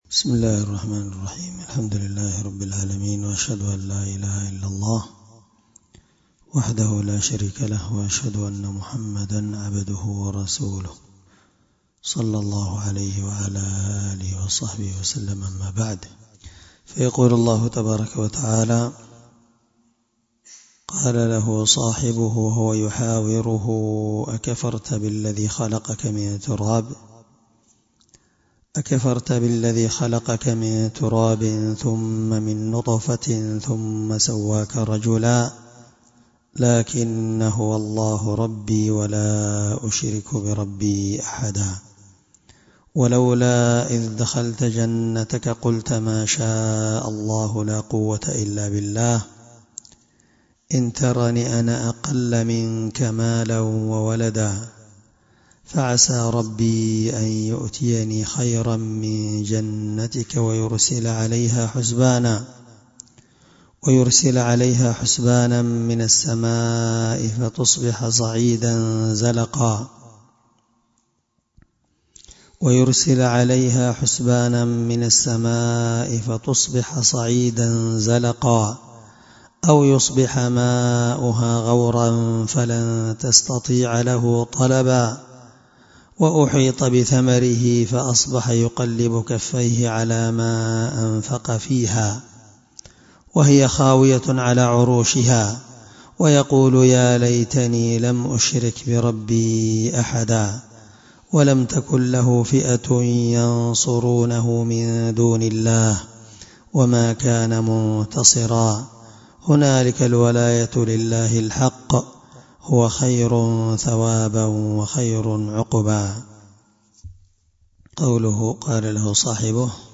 مع قراءة لتفسير السعدي